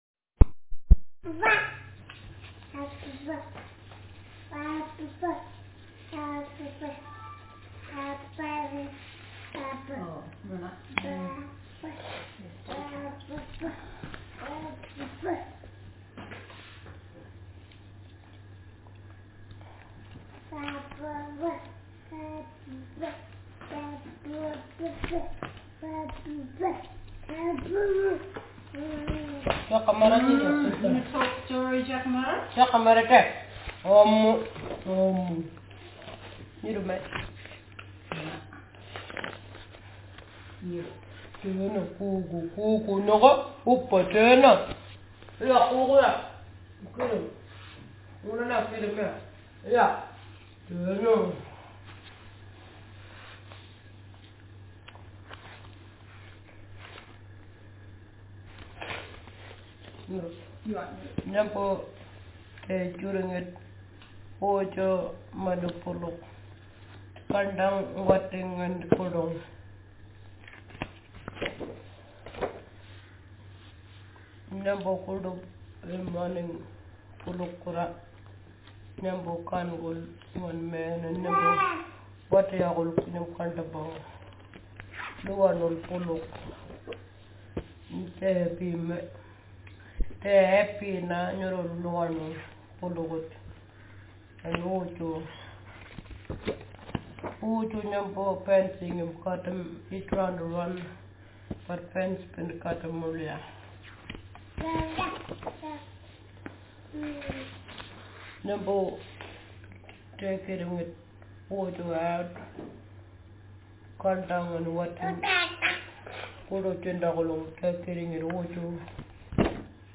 Speaker sex f Text genre stimulus retelling